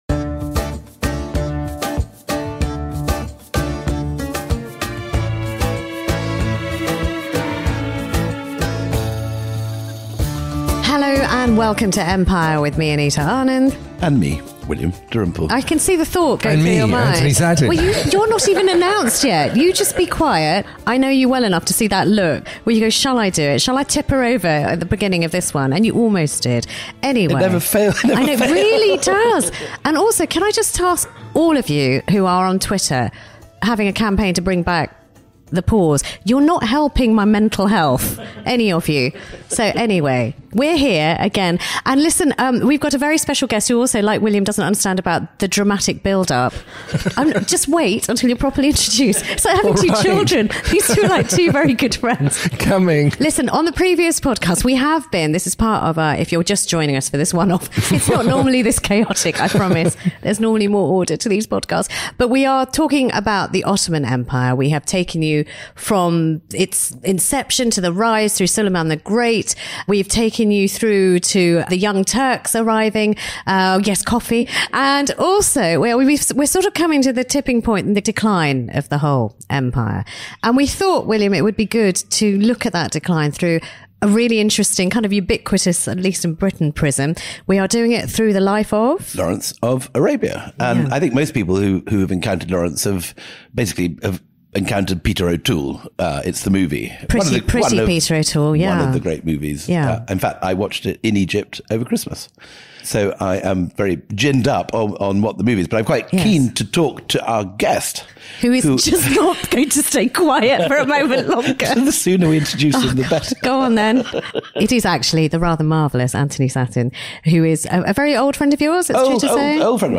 موسیقی متن حماسی
در استودیوهای Shepperton Studios لندن